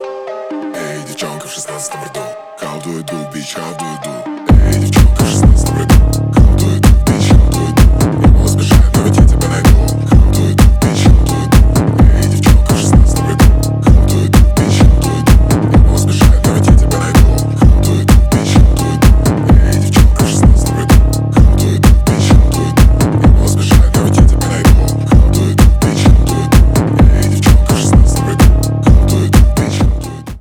рингтоны громкие